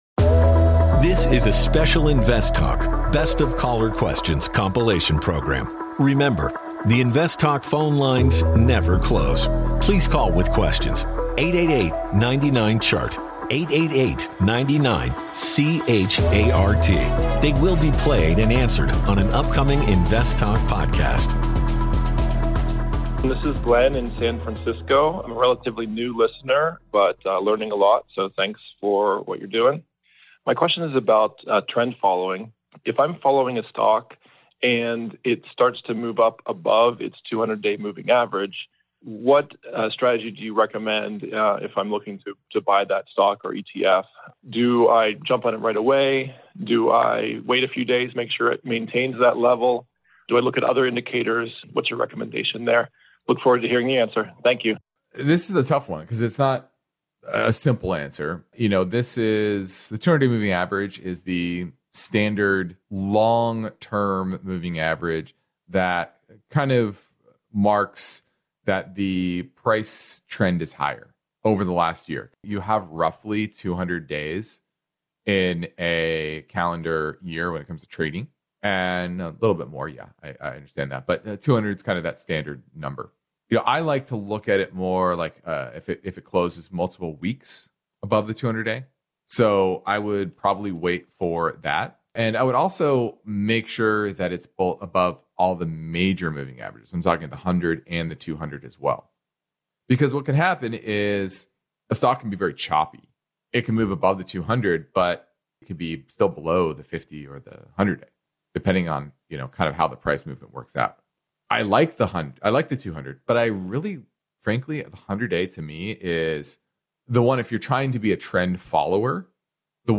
Best of Caller Questions